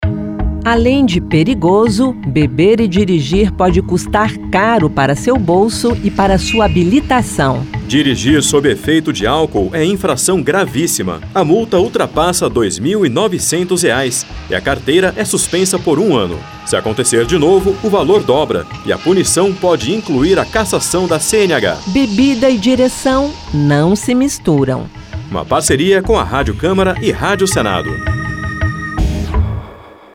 Spots e Campanhas